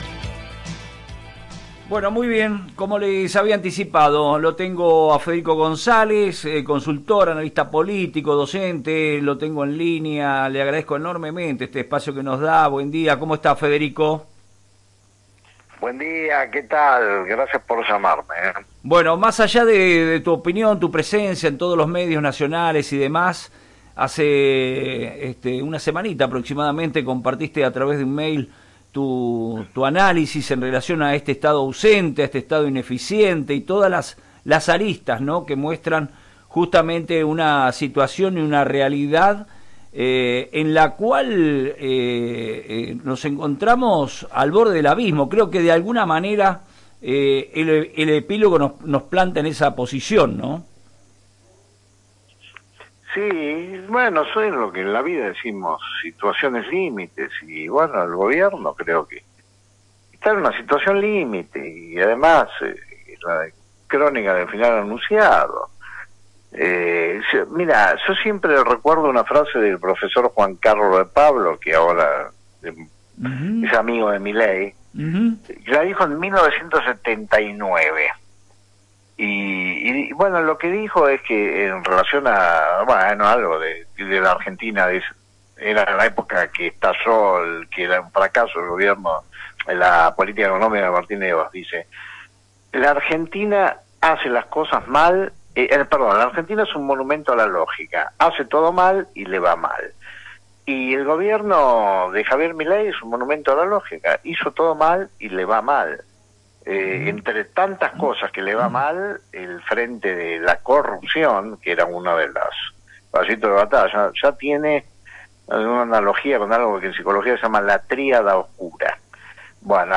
El consultor y docente mantuvo una nota en FM REFLEJOS dónde analizo la situación del gobierno nacional y su imagen frente al escándalo del narcogate del diputado Espert.